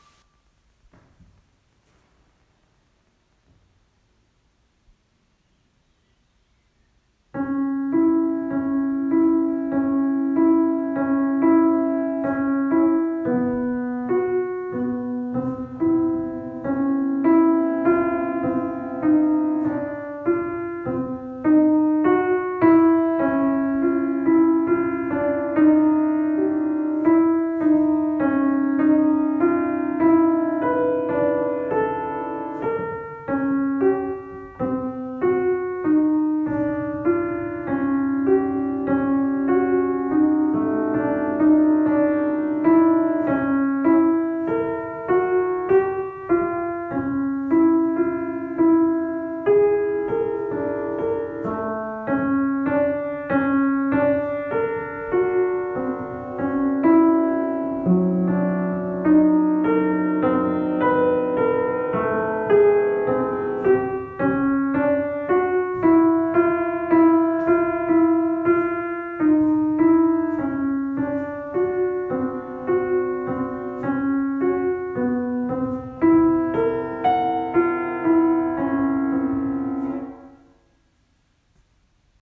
auf einem rein gestimmten Bösendorfer-Flügel
Ich spiele Ausschnitte aus dem Klavier II-Part.